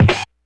kick04.wav